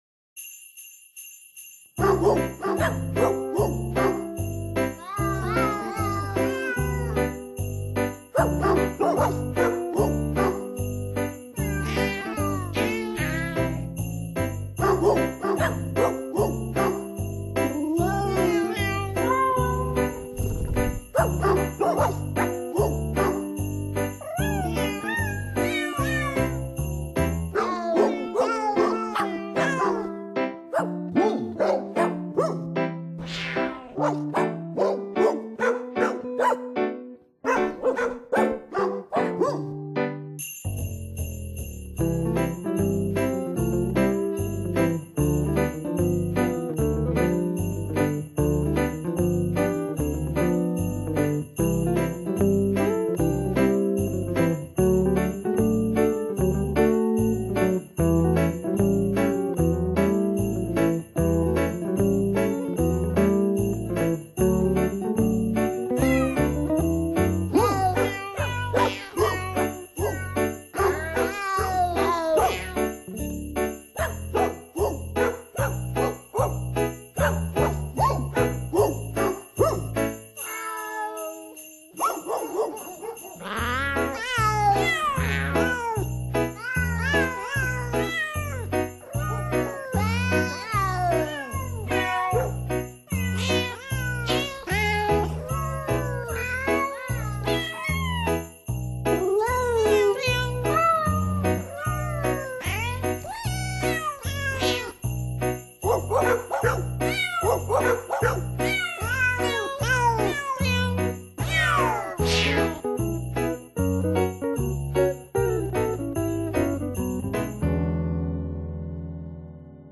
Genre: Comedy, Vocal, Christmas
Christmas melodies performed by cats!